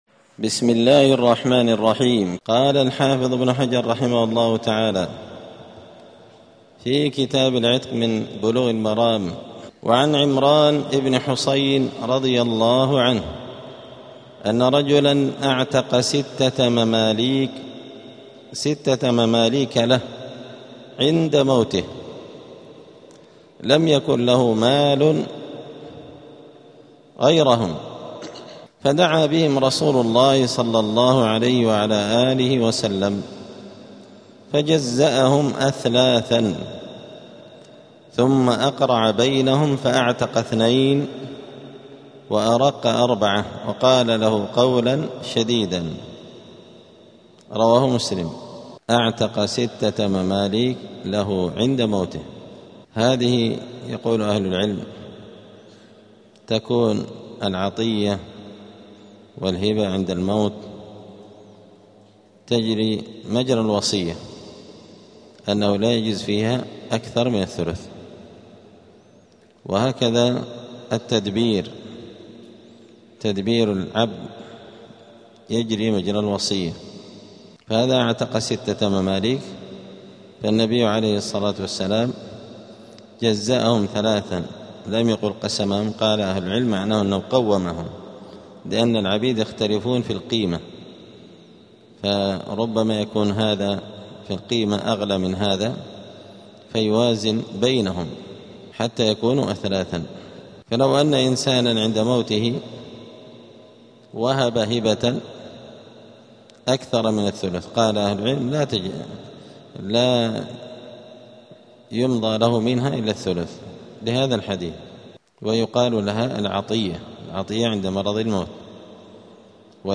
*الدرس الرابع (4) {الولاء لمن أعتق}*